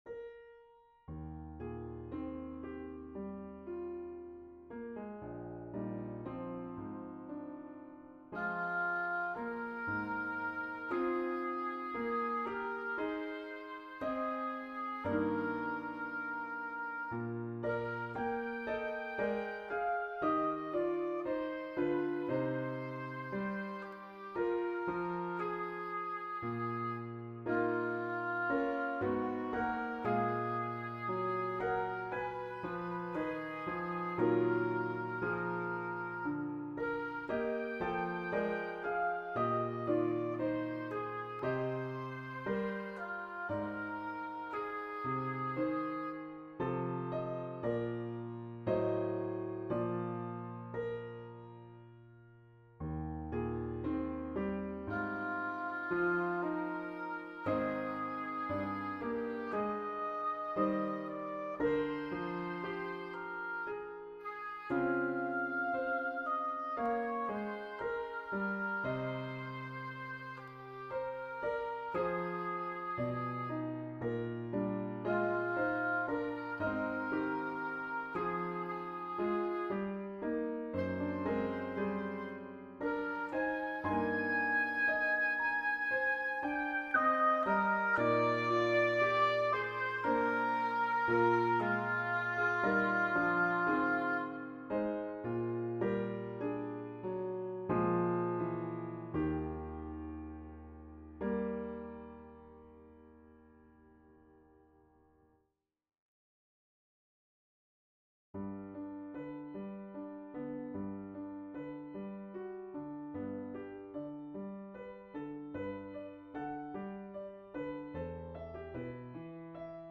Zwei Wandrers Nachtlieder - Choral, Vocal - Young Composers Music Forum